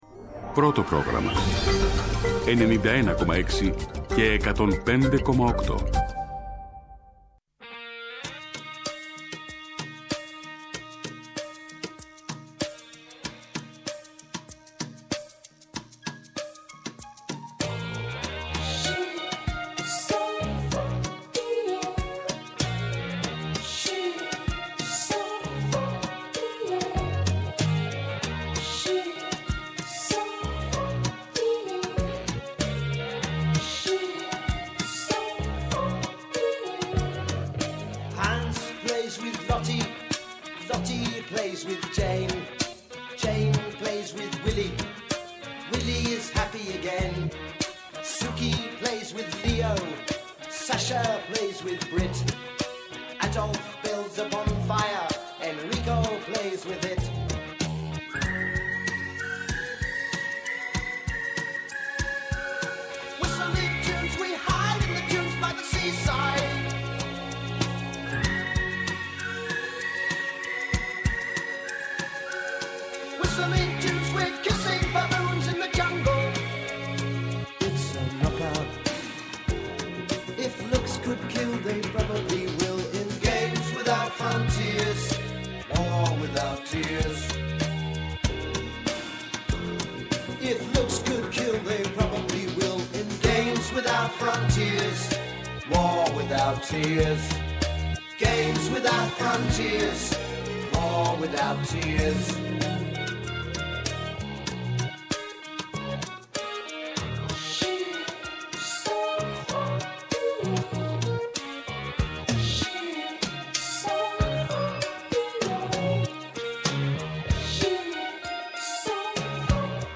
Ο Νίκος Καραθανασόπουλος, κοινοβουλευτικός εκπρόσωπος ΚΚΕ